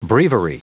Prononciation du mot breviary en anglais (fichier audio)
Prononciation du mot : breviary